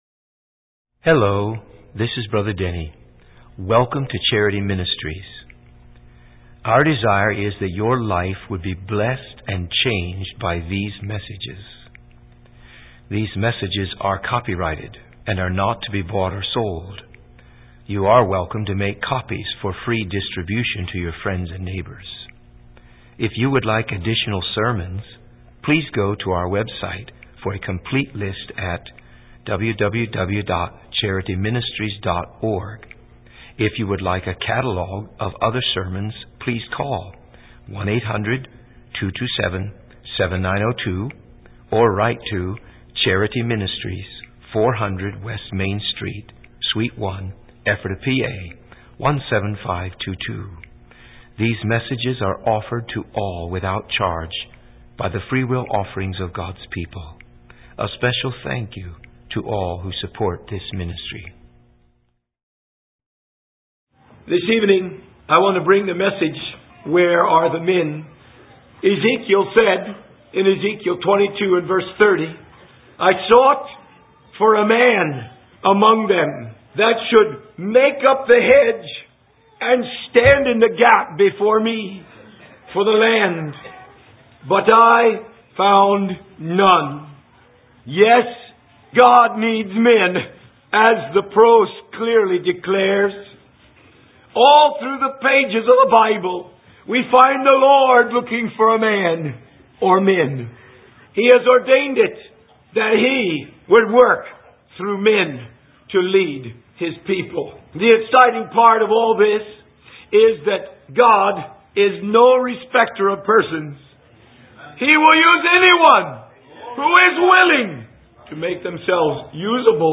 Sermon set